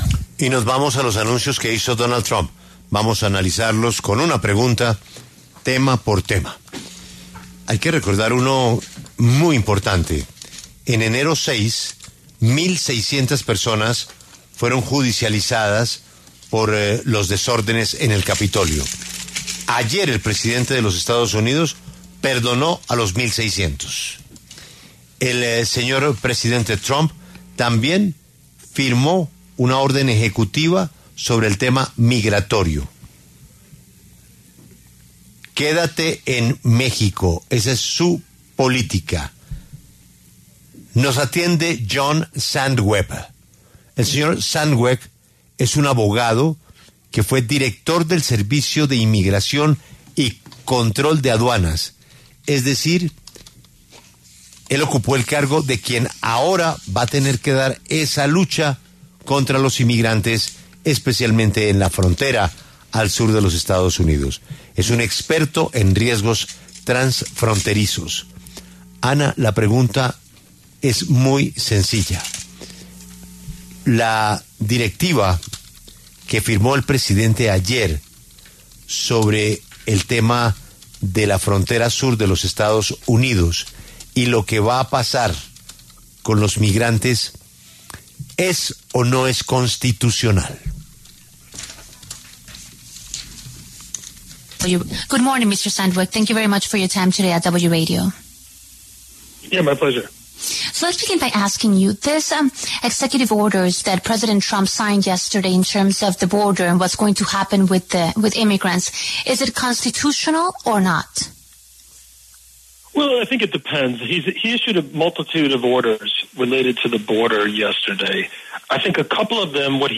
John R. Sandweg, abogado estadounidense que se desempeñó como director interino del Servicio de Inmigración y Control de Aduanas de EE.UU., conversó en los micrófonos de La W sobre las primeras órdenes ejecutivas que firmó el presidente Donald Trump en su primer día de mandato.